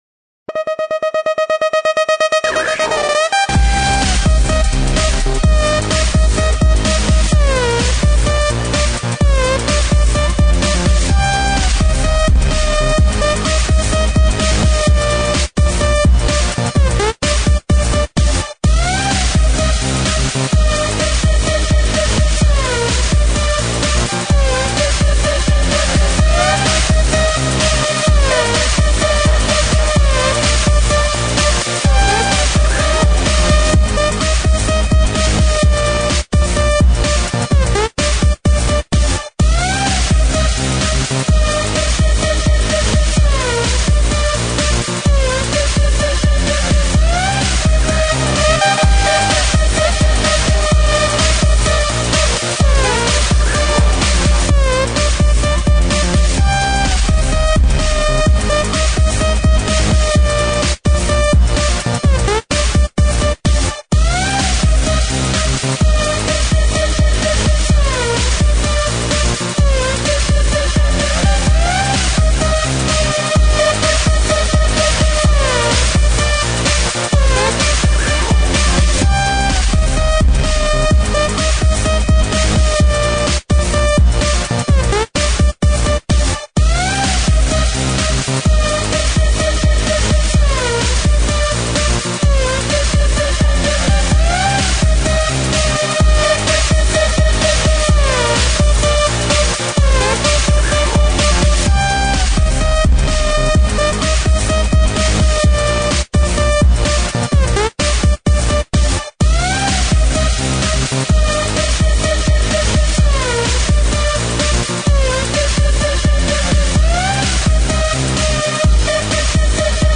Категория: ЭЛЕКТРОННАЯ